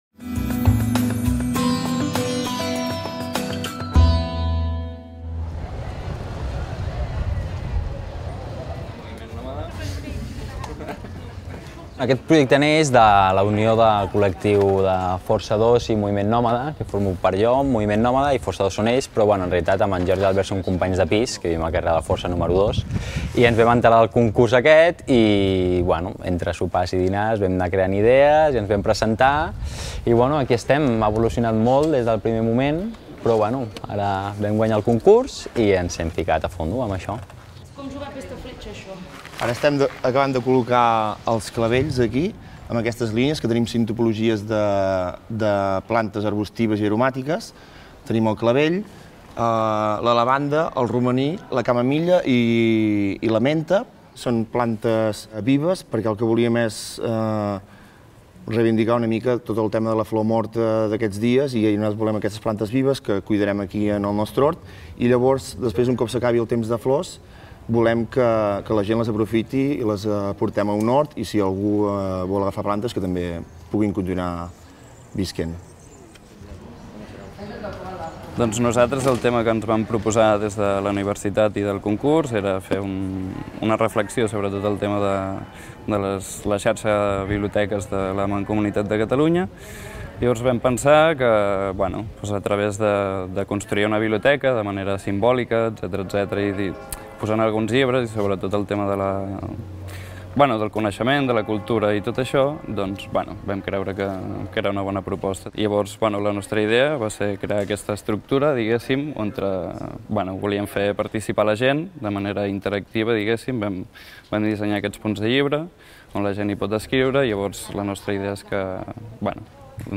Els estudiants d'arquitectura
guanyadors del concurs per decorar el pati del recotrat en motiu Temps de Flors de Girona expliquen els seu projecte durant el montatge